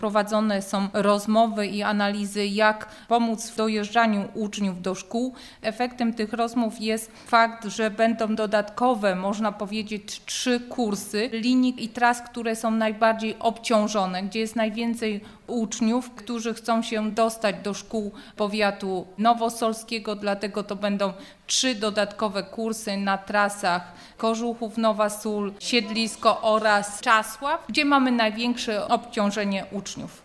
– Chodzi nam o bezpieczeństwo sanitarne związane z koronawirusem – powiedziała Sylwia Wojtasik, członek Zarządu Powiatu Nowosolskiego: